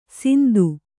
♪ sindu